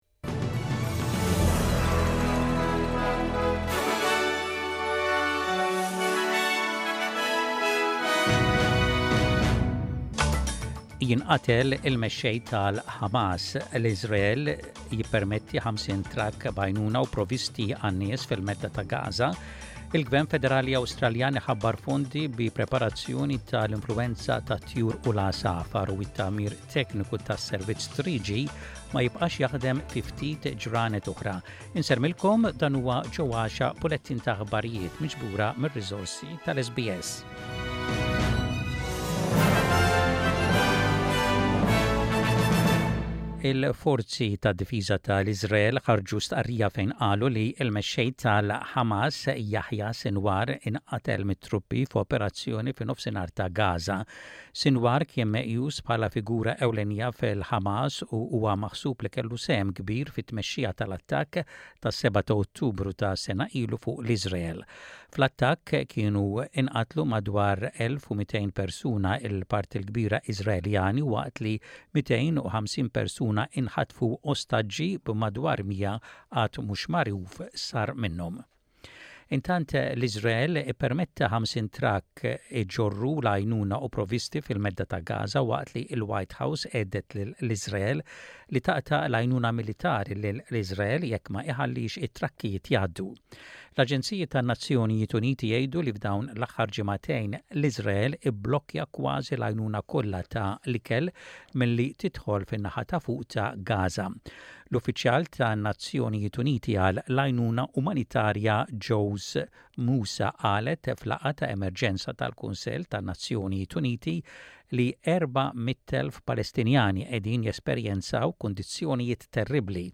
SBS Radio | Aħbarijiet bil-Malti: 18.10.24